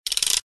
Звуки револьвера
Проворачиваем барабан револьвера при взведении курка